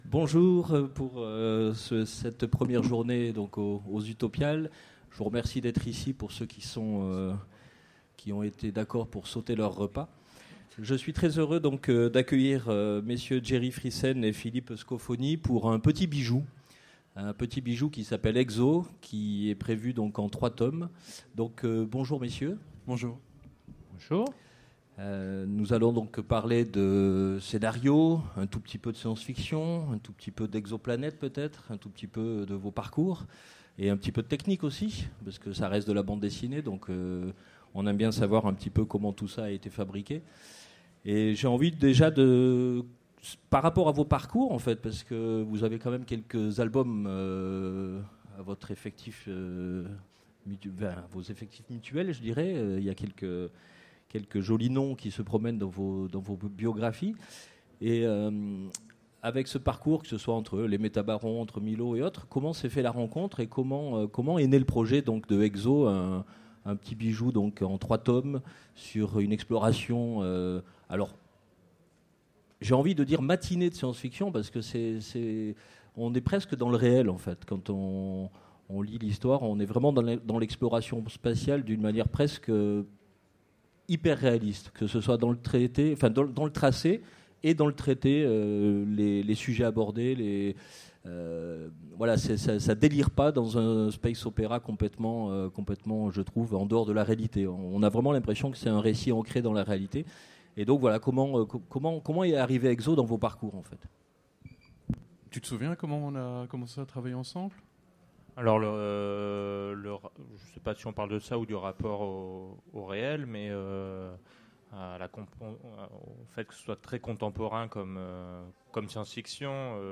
Utopiales 2017 : Conférence Exo